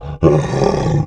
MONSTER_Pain_02_mono.wav